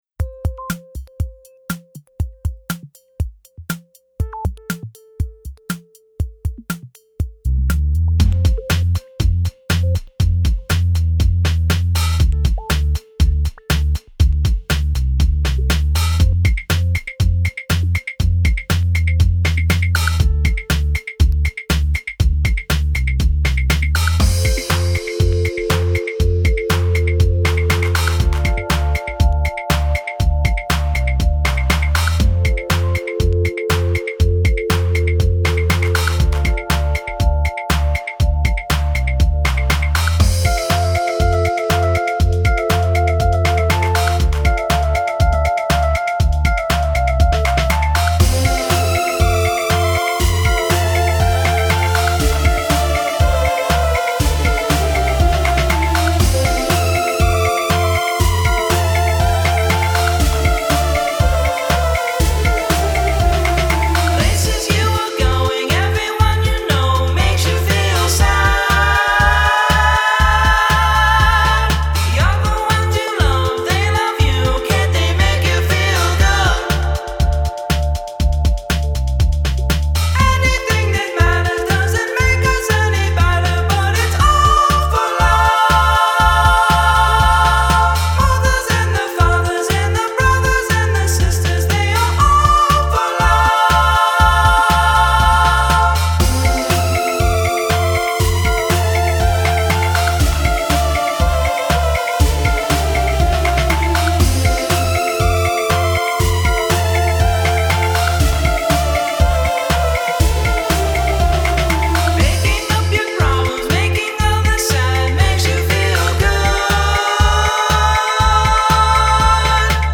synth pop confection, and yet w/ substance